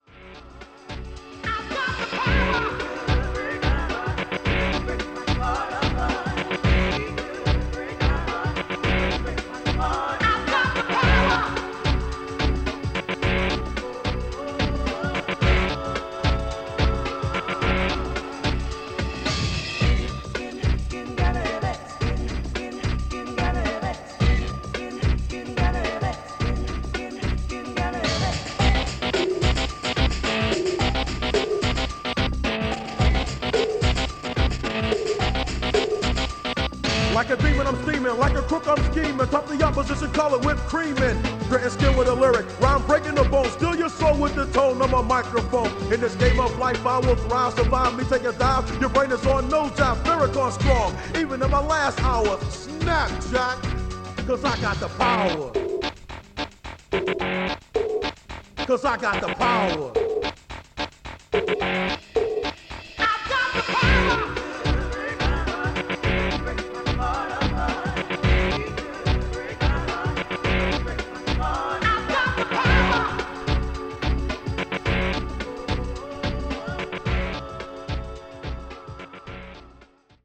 It comes with Dolby noise reduction and two large backlit VU meters in the front panel.
Below is a test record made with the CD-M01 and played back by it:
Type: 2-head, single compact cassette deck
AKAI-CS-M01-Test-Recording.mp3